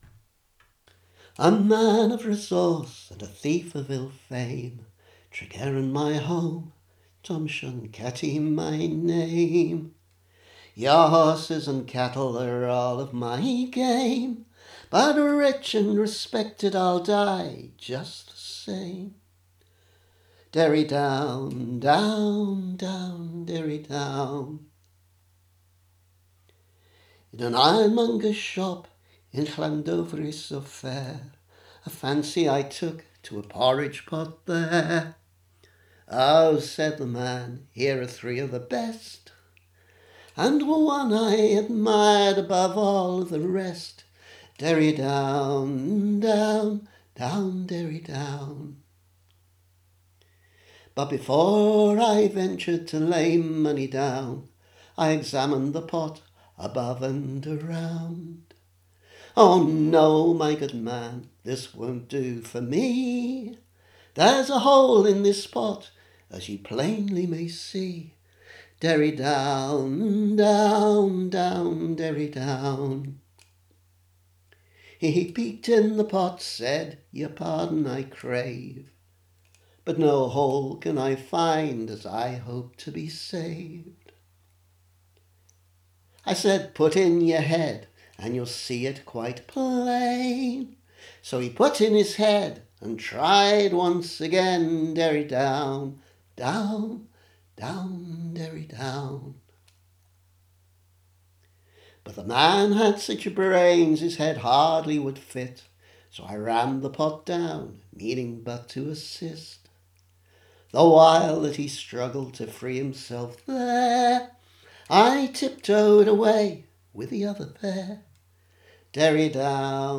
Three traditional tunes for the price of one